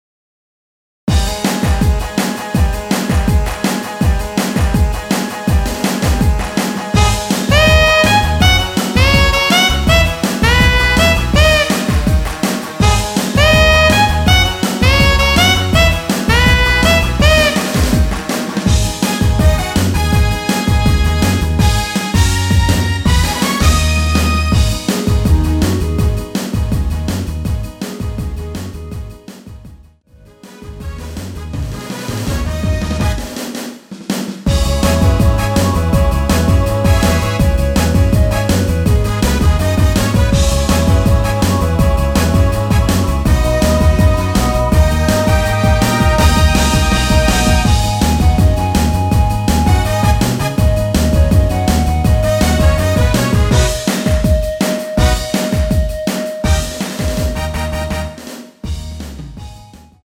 원키에서(+3)올린 멜로디 포함된 MR입니다.
G#m
앞부분30초, 뒷부분30초씩 편집해서 올려 드리고 있습니다.